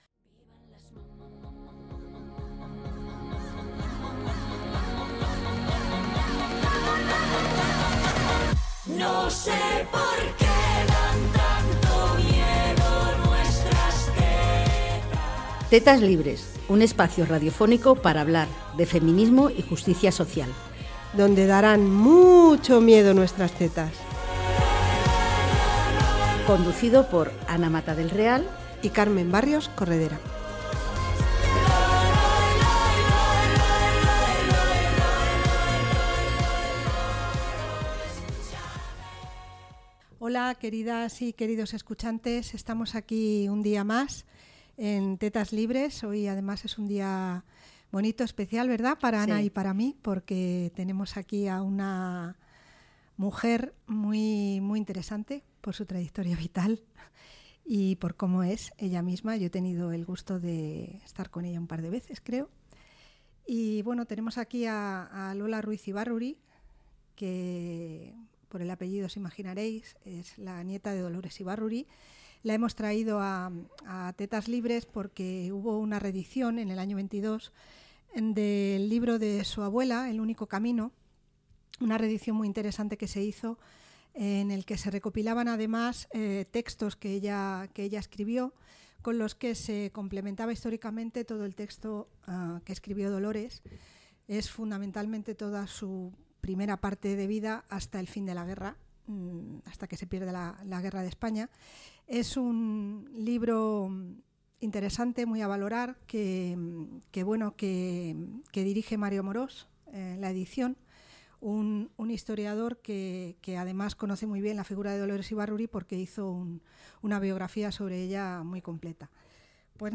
En la entrevista abordan la reedicion de las memorias de Pasionaria, "El único camino", obra que ha revisado el historiador Mario Amorós incluyendo contexto historico.